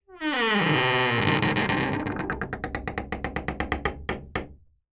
zapsplat_household_wooden_door_old_large_heavy_basement_creak_010_23371